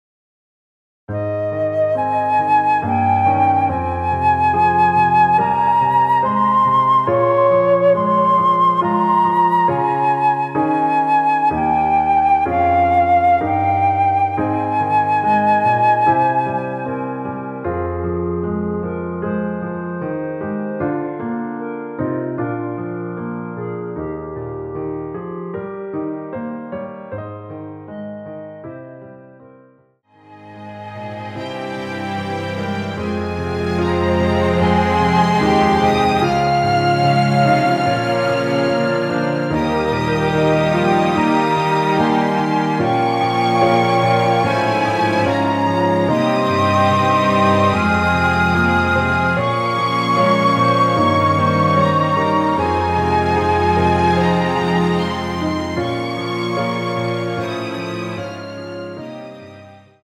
원키에서(-1)내린 멜로디 포함된 MR입니다.
Ab
앞부분30초, 뒷부분30초씩 편집해서 올려 드리고 있습니다.
중간에 음이 끈어지고 다시 나오는 이유는